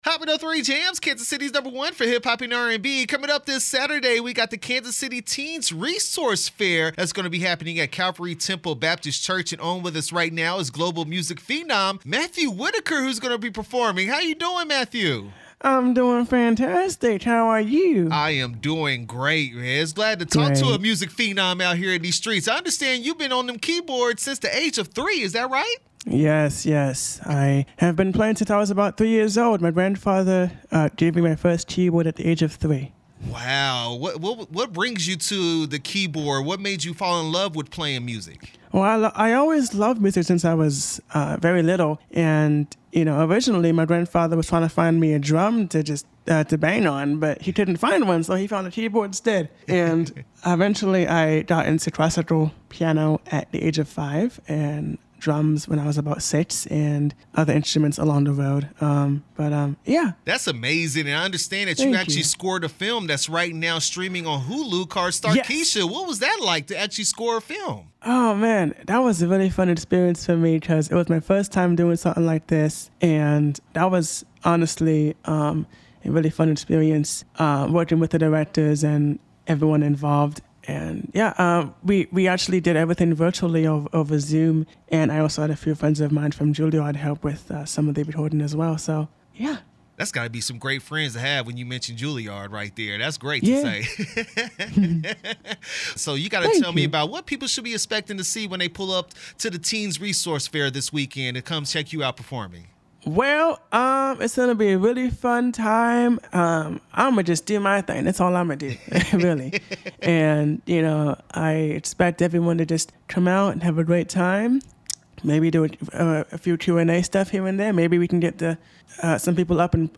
Calvary Temple Teens Resource Fair/Matthew Whitaker interview 4/3/24